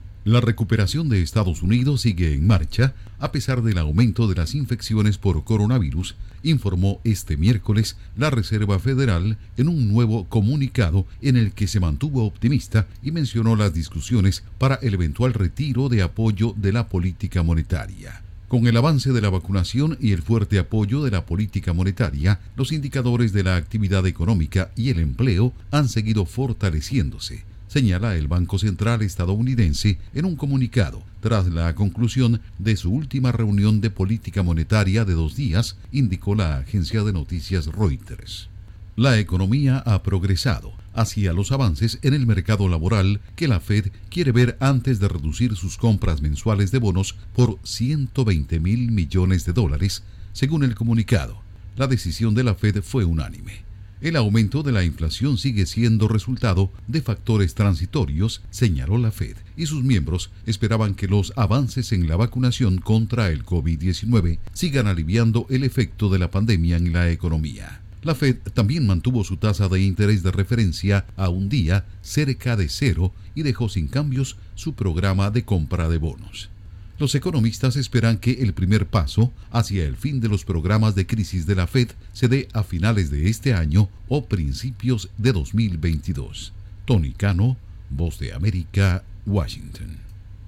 Informa desde la Voz de América en Washington